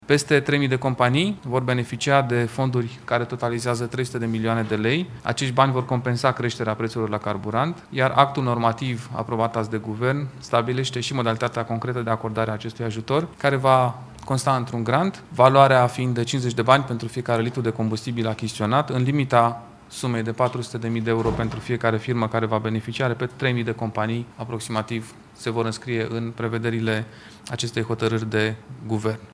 Tot miercuri, Guvernul a aprobat și proiectul prin care firmele de transport mărfuri și persoane pot primi de la stat o reducere de 50 de bani pentru litrul de carburant. Din nou, Dan Cărbunaru: